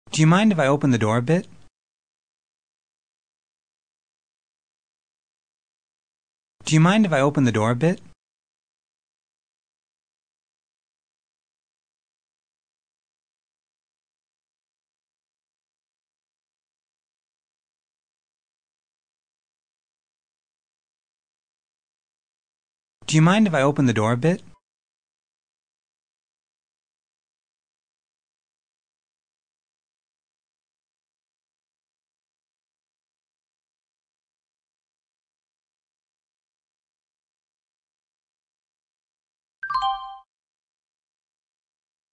Speech Communication Dictation
Form-Focused Dictation 1: Wh. vs. Yes/No Questions (intonation patterns)